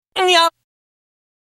Human
Voice(23K) -